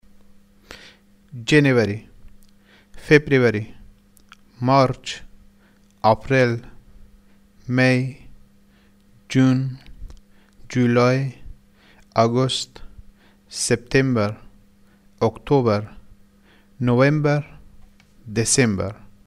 (Listen to the month names as pronounced in two cities in Afghanistan.)
Dialect of Herat